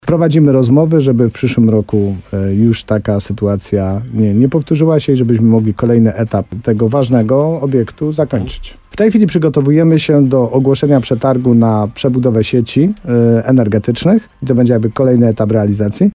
Wójt Tadeusz Królczyk zapewniał w programie Słowo za Słowo w radiu RDN Nowy Sącz, że nie zatrzymało to prac budowlanych.